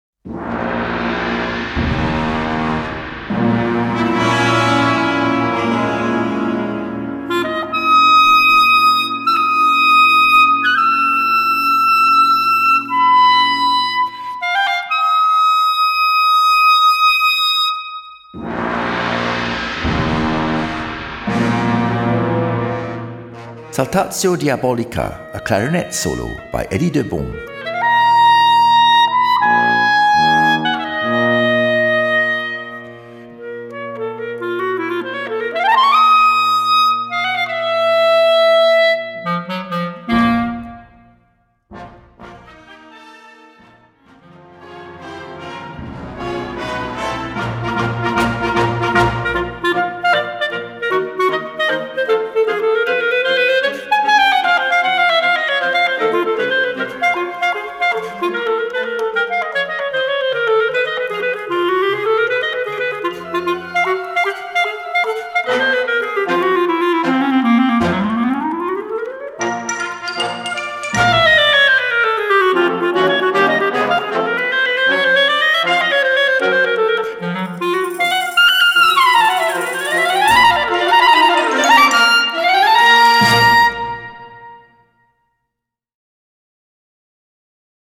Gattung: Solo für Klarinette
Besetzung: Blasorchester